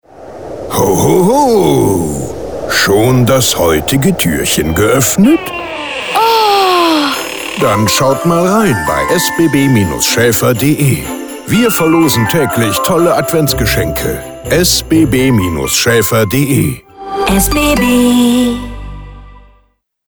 Sound Logo im Audio Spot